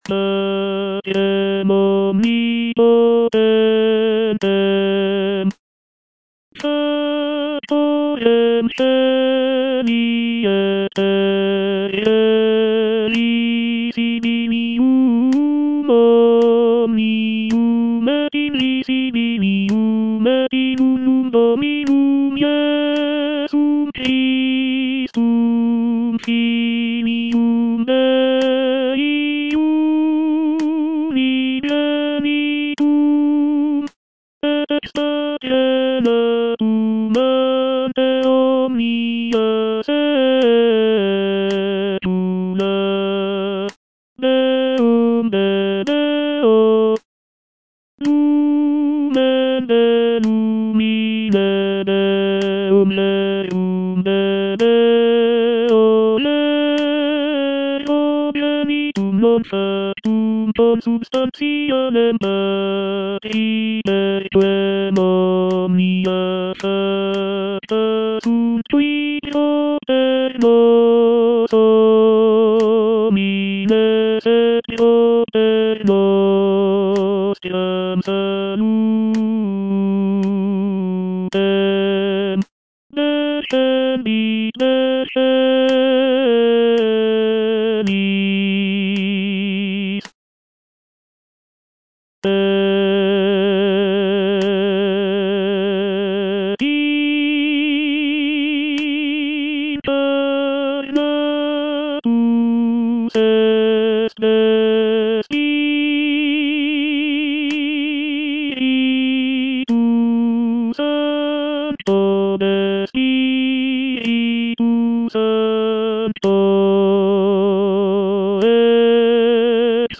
T.L. DE VICTORIA - MISSA "O MAGNUM MYSTERIUM" Credo - Tenor (Voice with metronome) Ads stop: auto-stop
"Missa "O magnum mysterium"" is a choral composition by the Spanish Renaissance composer Tomás Luis de Victoria.
It is renowned for its rich harmonies, expressive melodies, and intricate counterpoint.